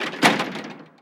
Cerrar una puerta de cristal
Sonidos: Acciones humanas
Sonidos: Hogar